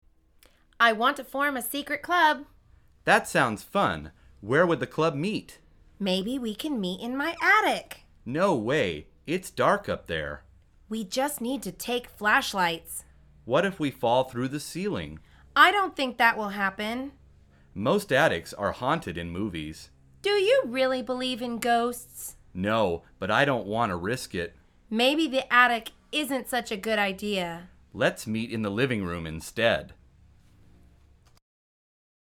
مجموعه مکالمات ساده و آسان انگلیسی – درس شماره چهاردهم از فصل مسکن: اتاق زیر شیروانی